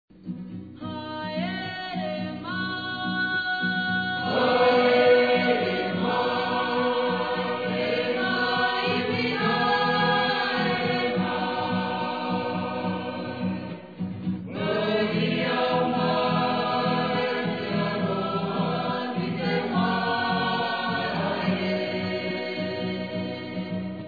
SUCH a turnout and SUCH a great sound.
We look forward to singing with you, your loved ones, your not-so-loved ones and anyone else who loves to sing in glorious unaccompanied harmony